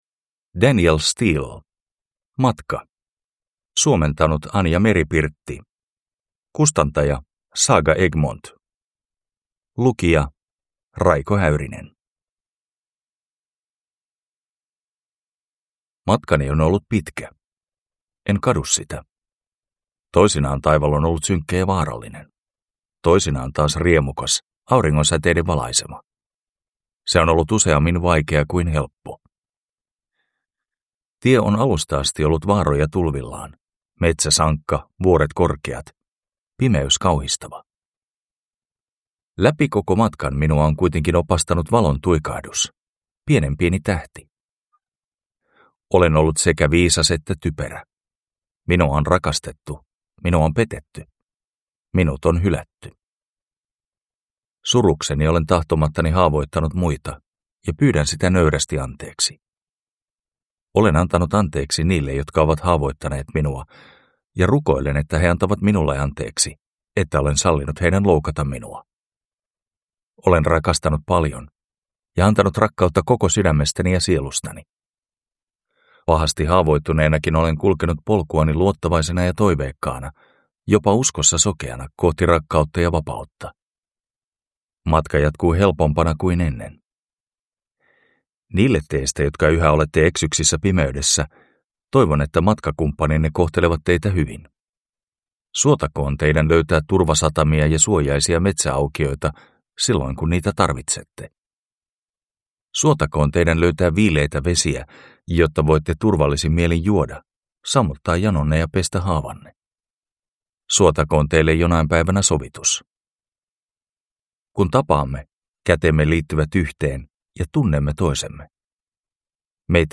Matka – Ljudbok